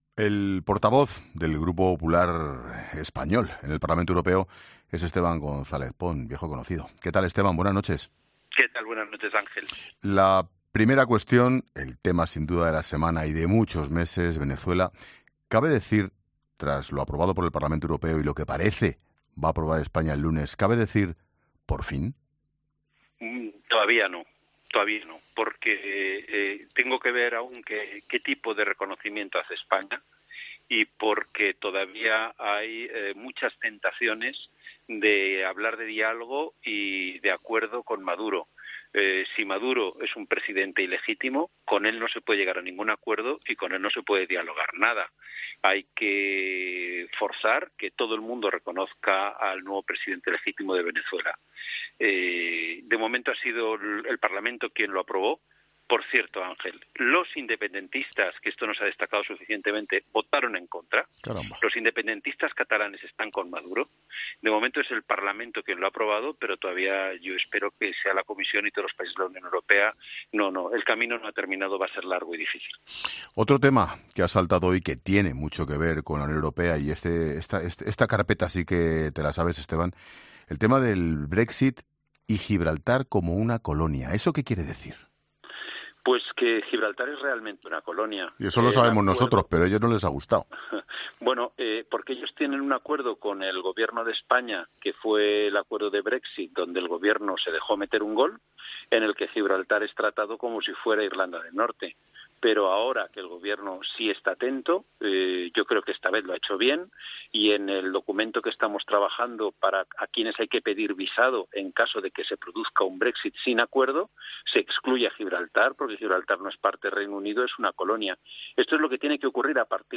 El portavoz del Partido Popular en el Parlamento Europeo, Esteban González Pons, ha estado este viernes en 'La Linterna' para comentar los asuntos de actualidad con la mirada puesta en clave europea.